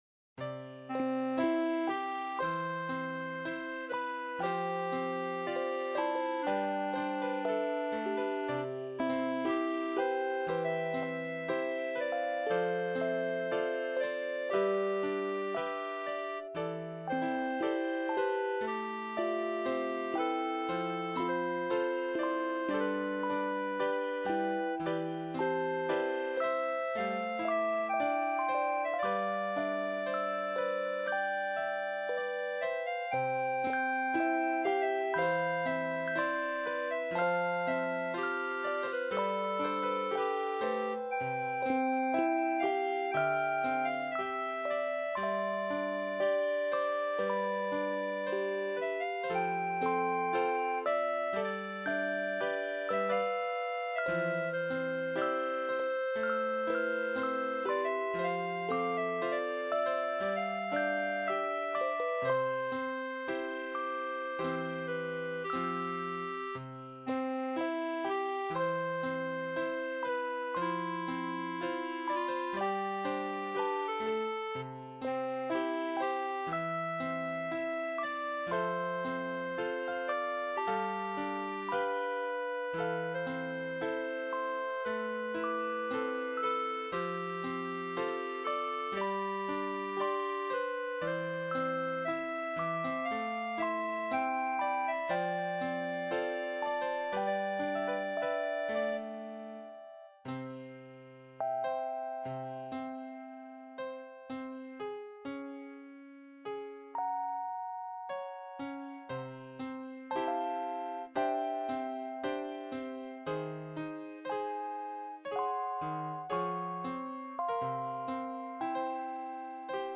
Clarinet, Electric Piano, soppy style